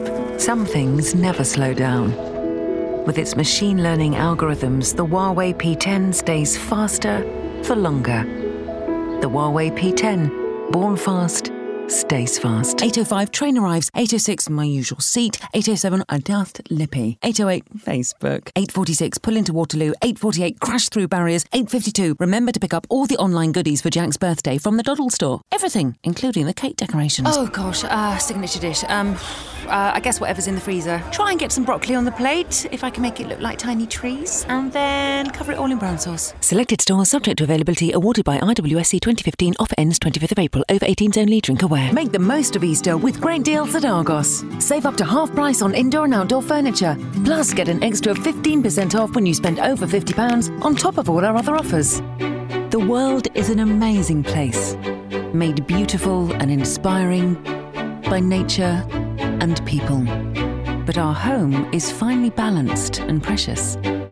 Commercial Showreel
Showreel